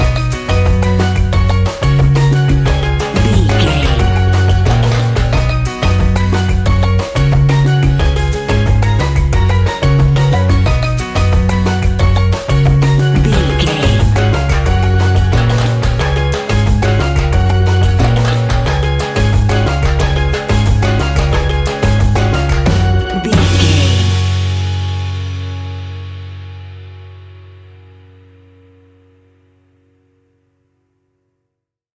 Uplifting
Ionian/Major
E♭
steelpan
drums
percussion
bass
brass
guitar